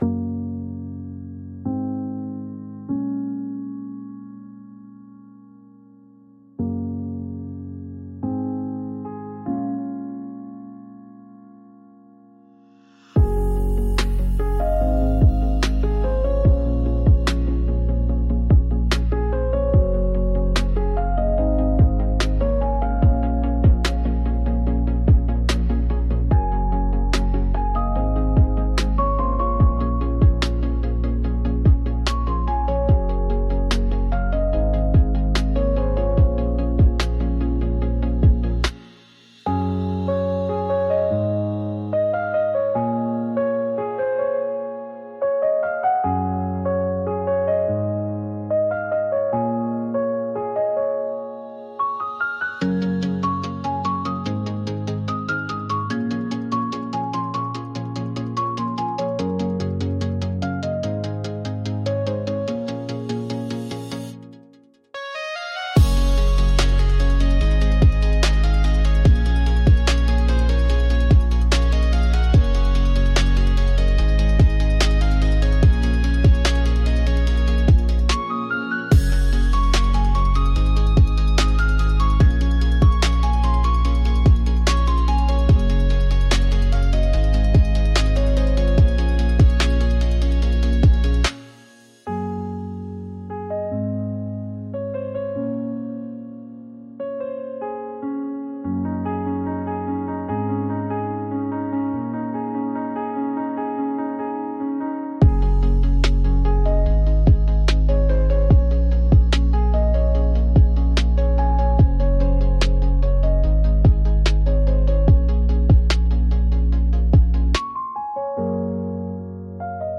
C# Major – 146 BPM
Hip-hop
Pop
Rnb